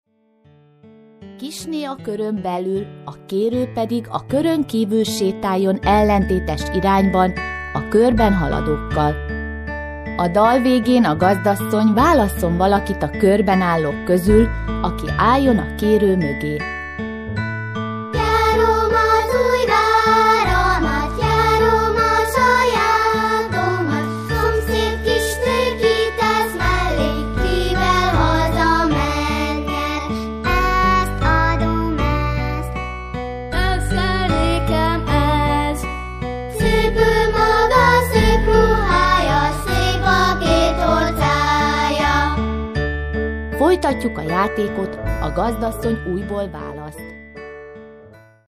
Az albumon a dalokat kisiskolások adják elő.